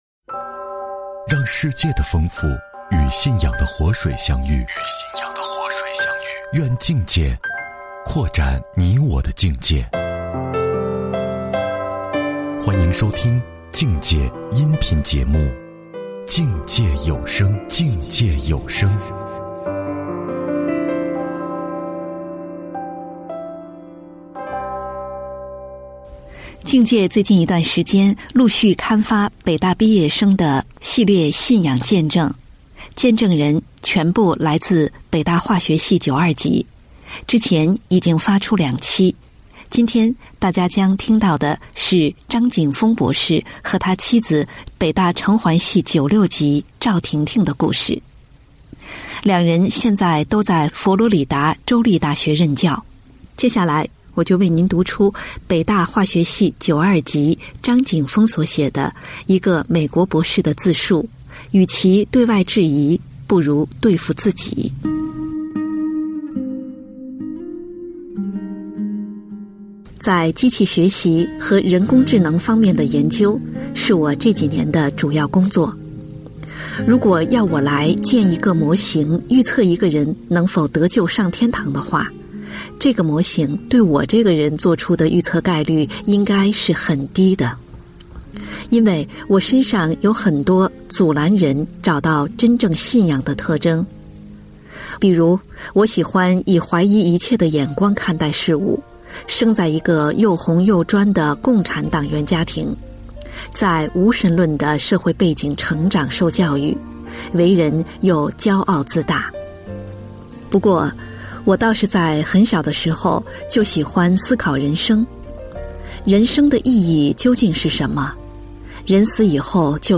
播音